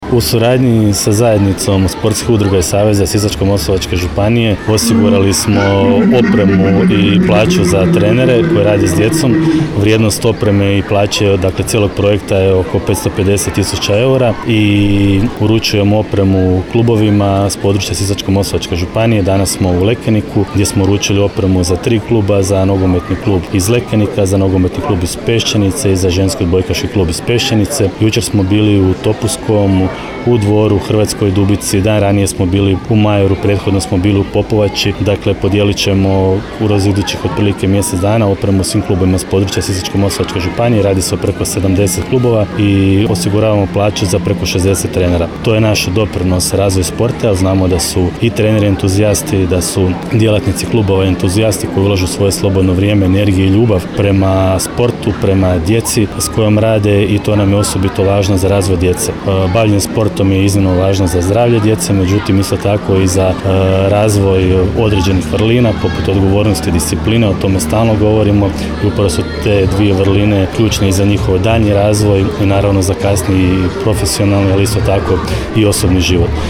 U srijedu, 16. travnja, 2025. godine, u Lekeniku u dvorištu OŠ Mladost župan Ivan Celjak je sportskim klubovima NK Lekenik, NK Pešćenica i Športskom odbojkaškom klubu „Pešćenica“ podijelio komplete sportske opreme za mlade sportaše.